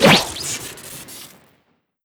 blast_dodge.wav